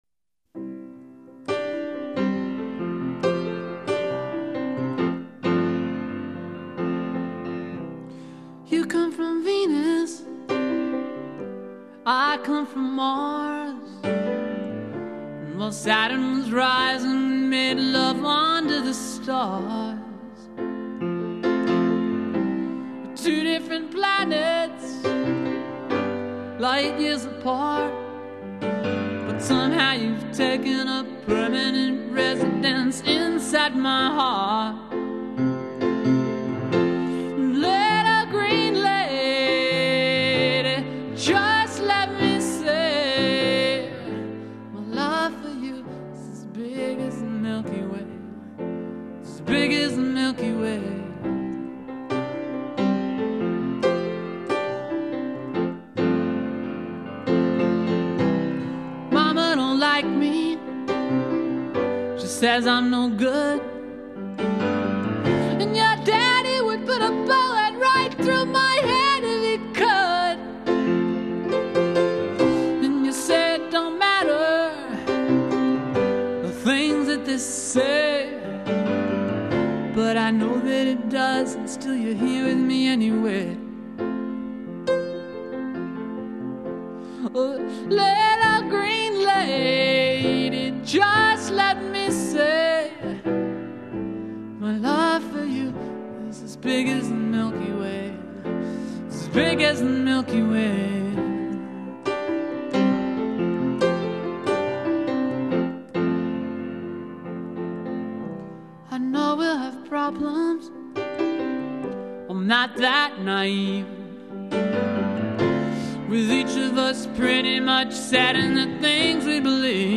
(demo)
Vocals and Piano